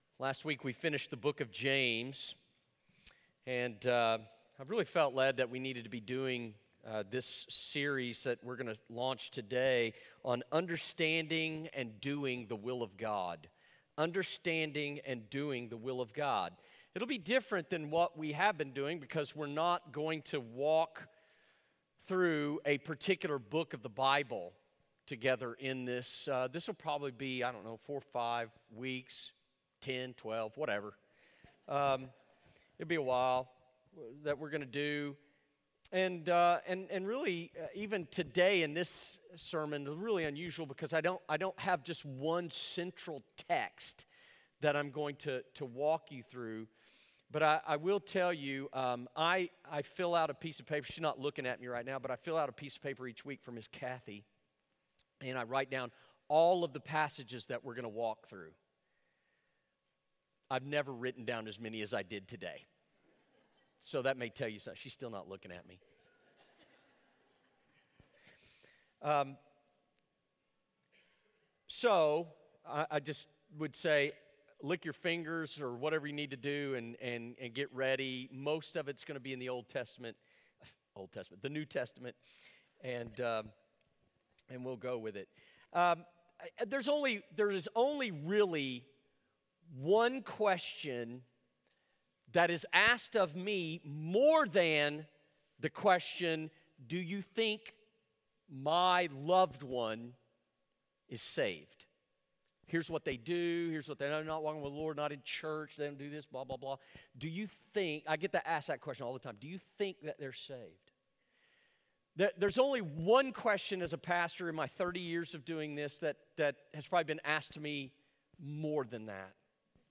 Understanding and Doing the Will of God (Sermon only) 2.20.2022